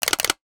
NOTIFICATION_Click_13_mono.wav